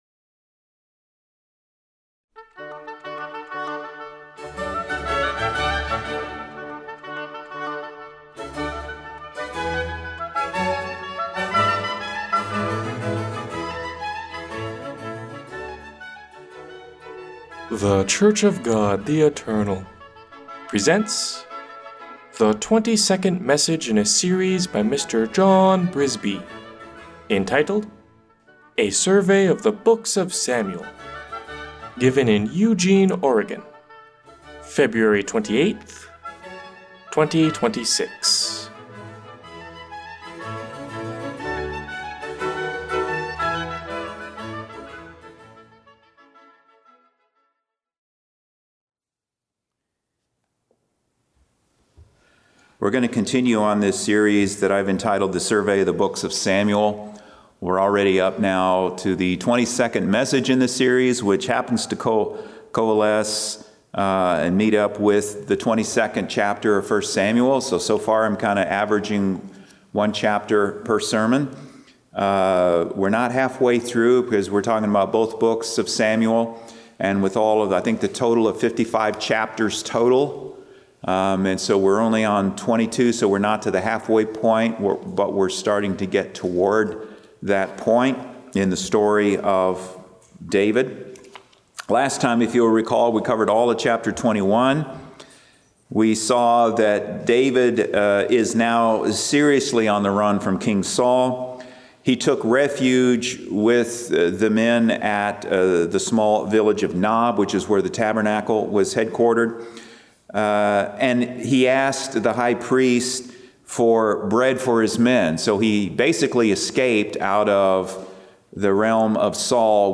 This section catalogs weekly Sabbath sermons presented in Eugene, Oregon for the preceding twelve month period, beginning with the most recent.